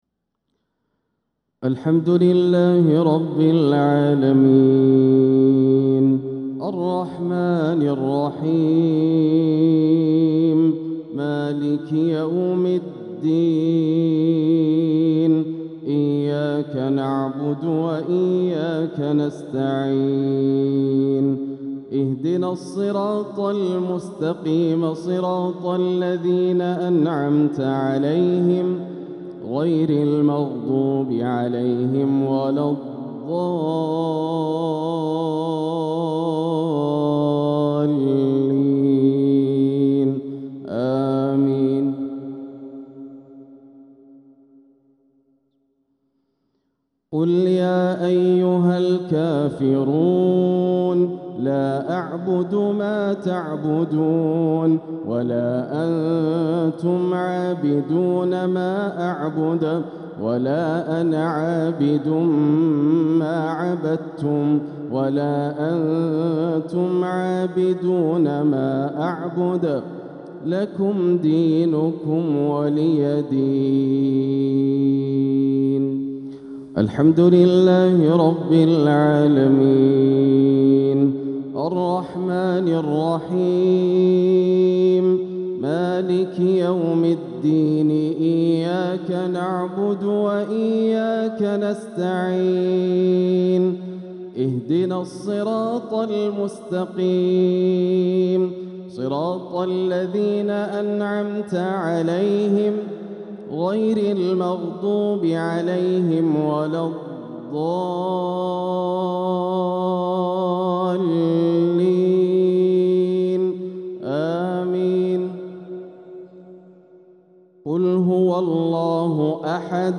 سورتي الكافرون والإخلاص | مغرب السبت 8-6-1447هـ > عام 1447 > الفروض - تلاوات ياسر الدوسري